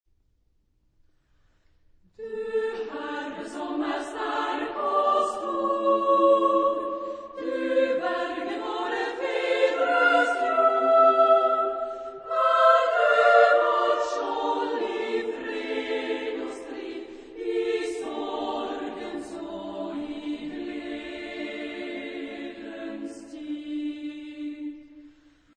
Genre-Style-Forme : Populaire ; Lied ; Sacré
Caractère de la pièce : andante
Type de choeur : SSA  (3 voix égales de femmes )
Tonalité : ré mineur